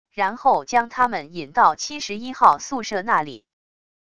然后将他们引到71号宿舍那里wav音频生成系统WAV Audio Player